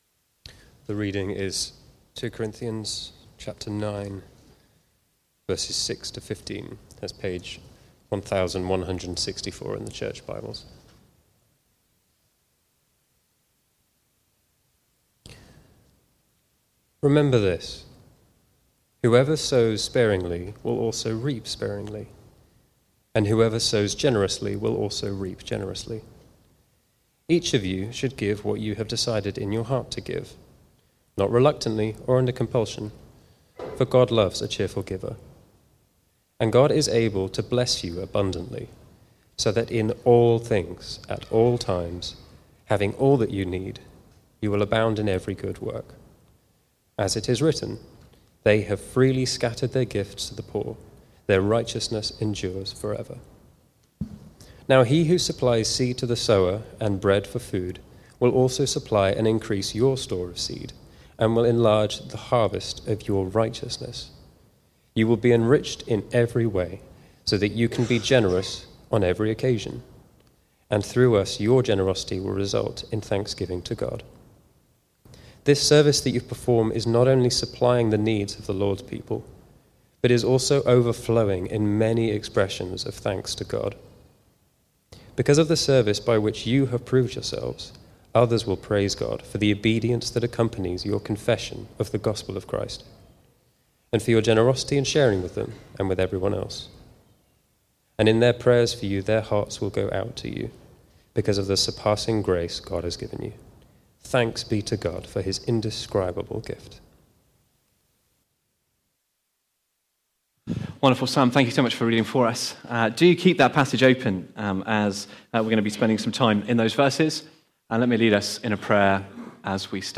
Recorded at Woodstock Road Baptist Church on 14 December 2025.